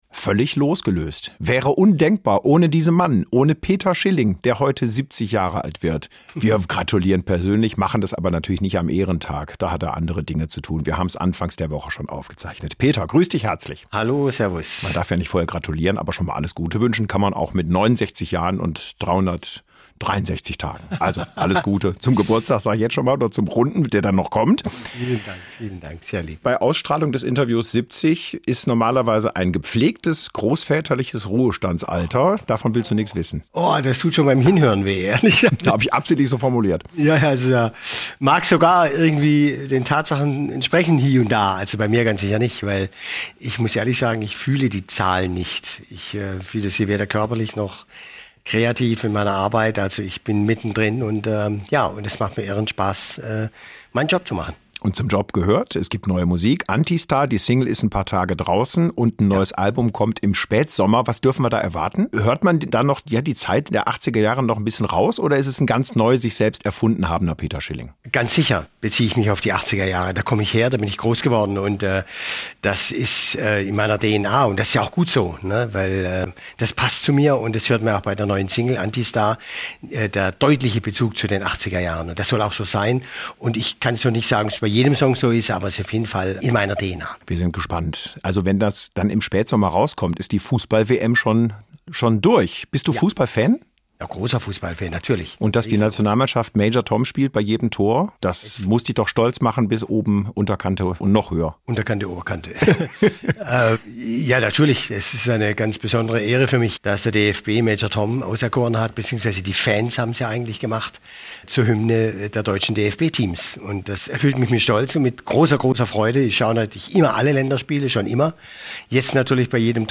Peter Schilling im SWR1 Interview
Interview mit